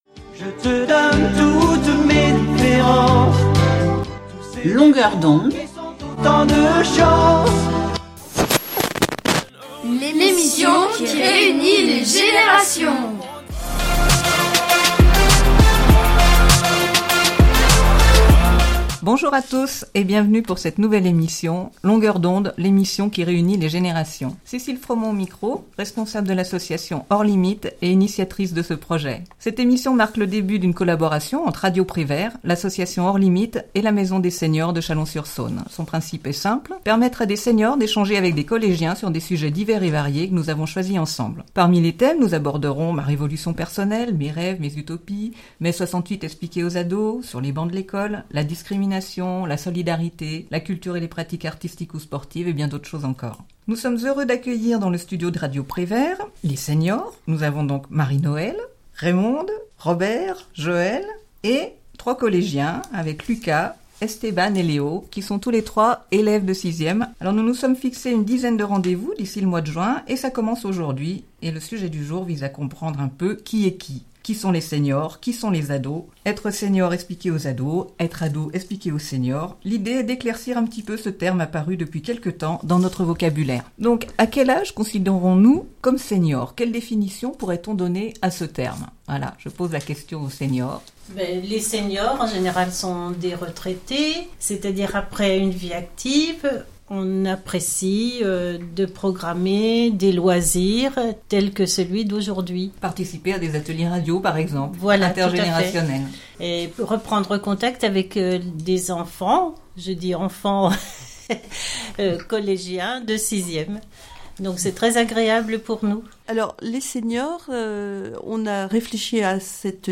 🕐 Il y a quelques jours, les seniors et les adolescents se sont retrouvés pour l’enregistrement de leur première émission dans les studios de Radio Prévert.